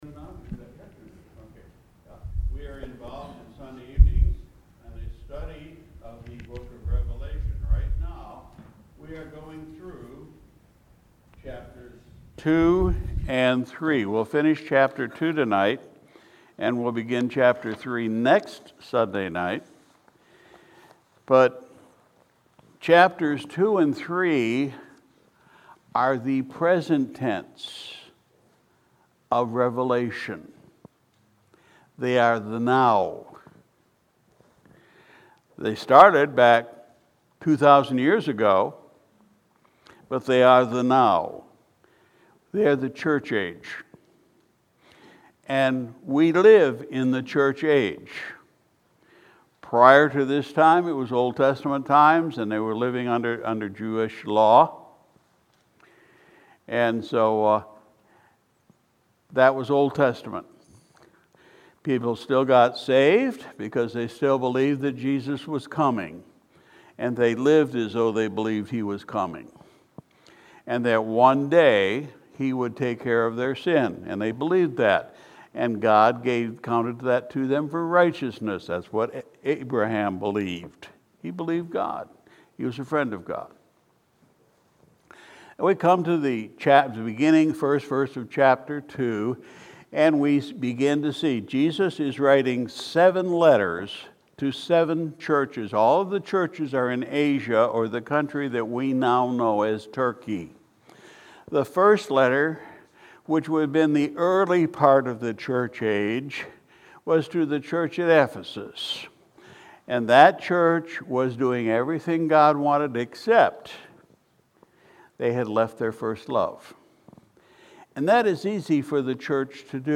January 2, 2022 Sunday Evening Service We continued our study in the Book of Revelation (Revelation 2:18-29)